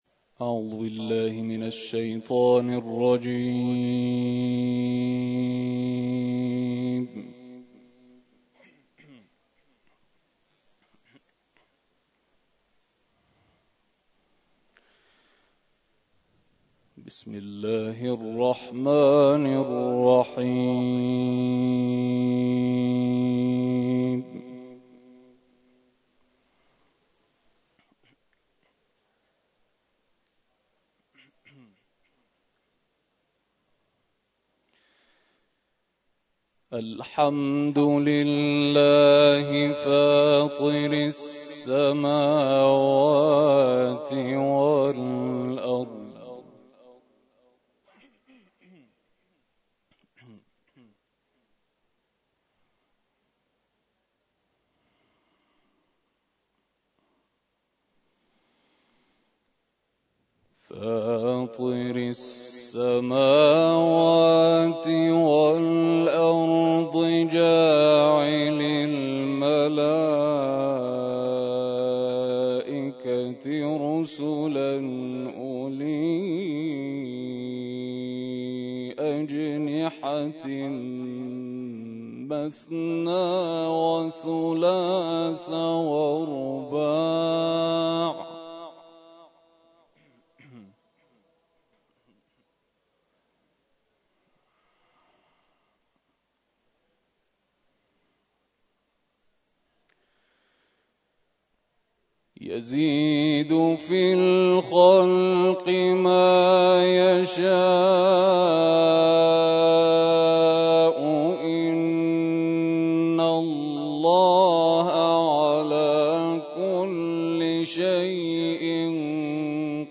در ادامه شنونده این تلاوت زیبا باشید.